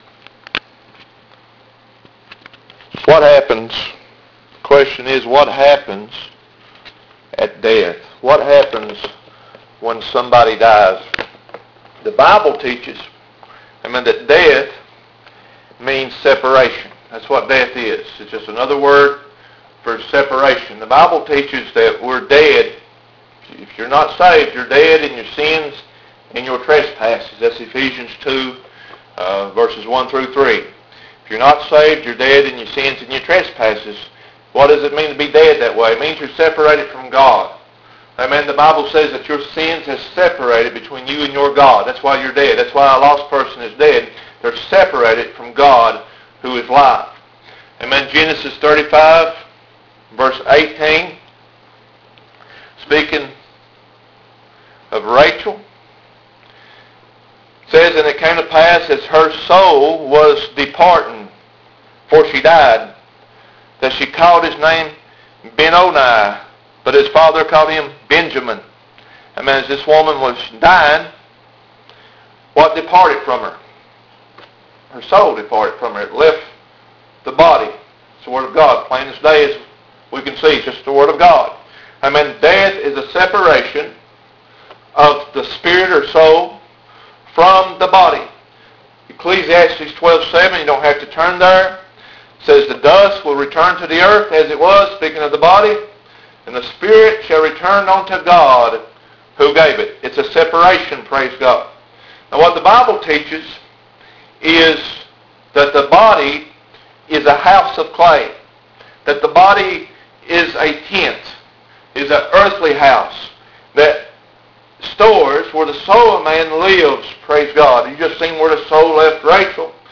THE TRUTH ABOUT DEATH AND HELL The Truth about Death - ----Audio Sermon